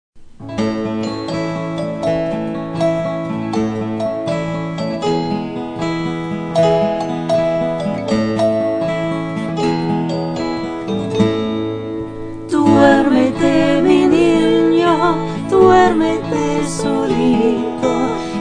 Traditional Spanish Song Lyrics and Sound Clip